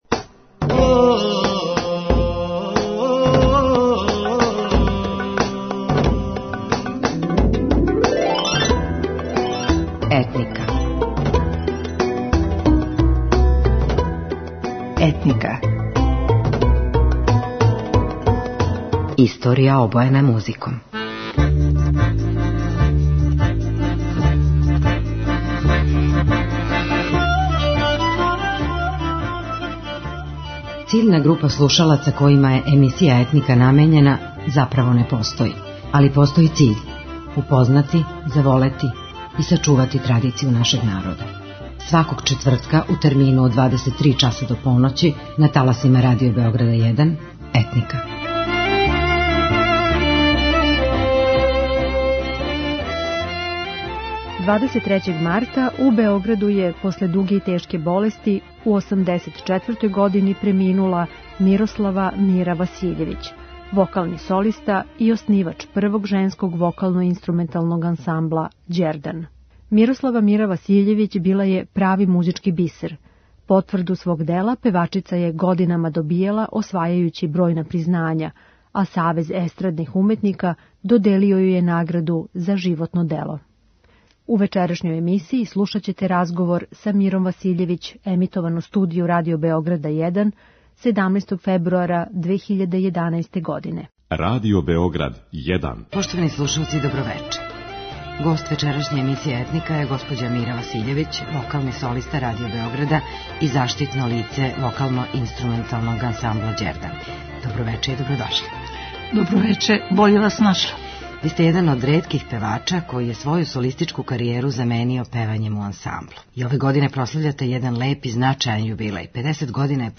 У вечерашњој емисији слушаћете разговор